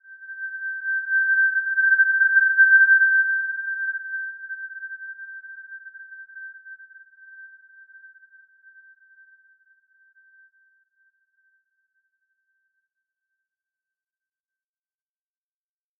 Simple-Glow-G6-mf.wav